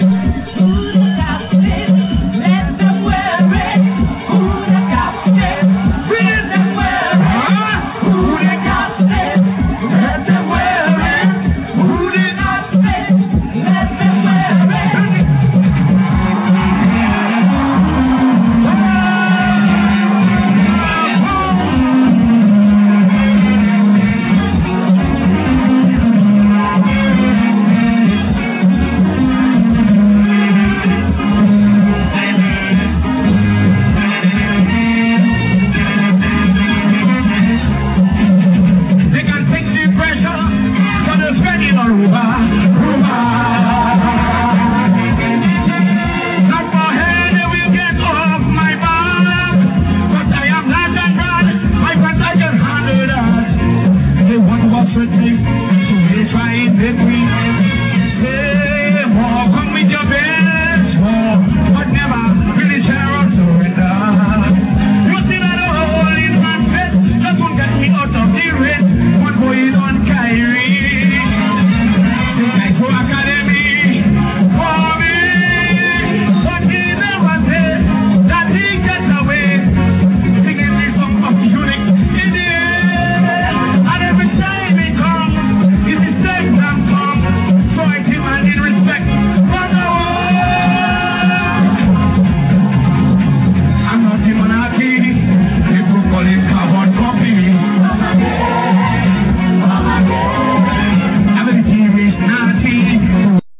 Click on these MEMORABLE CAISO SNIPETS(soon on DVD)